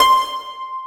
WATERHARP LM 1.wav